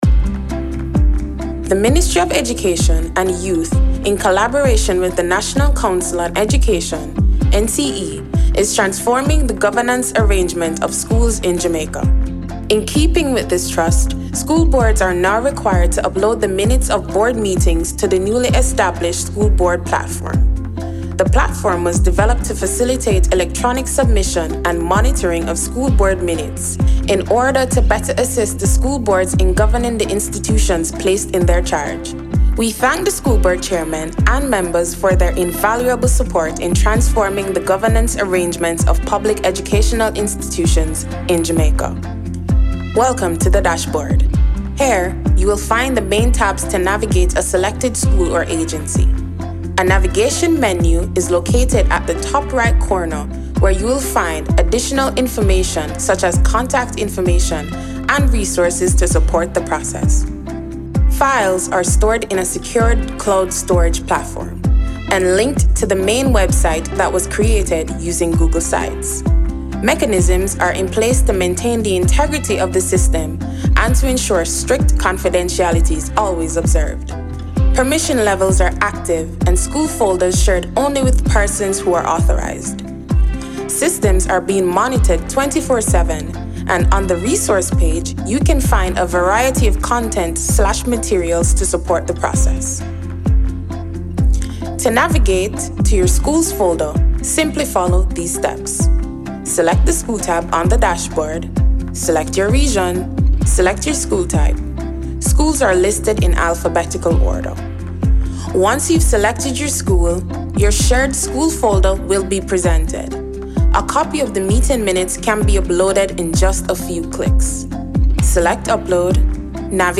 NCE-COMMERCIAL-FINAL.mp3